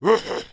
damage_4.wav